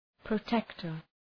Προφορά
{prə’tektər} (Ουσιαστικό) ● προστάτης